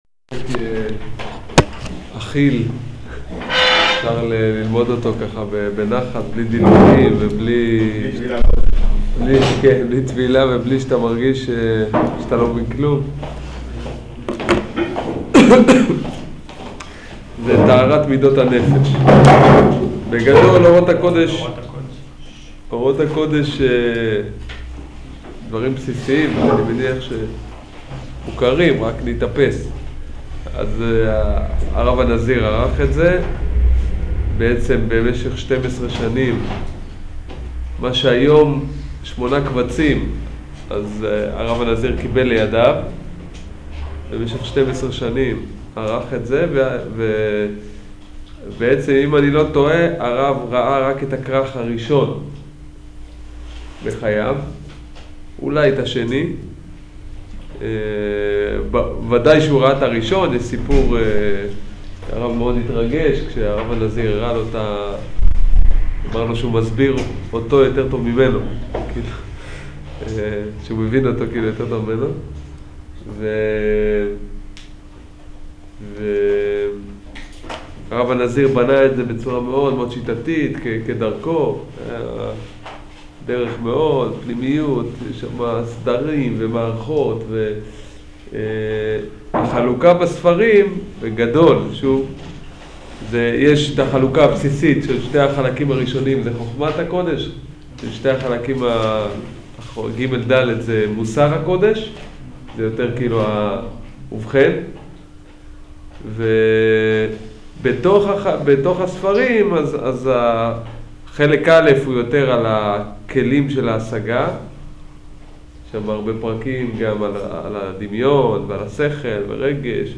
שיעור פתיחה לעבודת המידות - טהרת מידות הנפש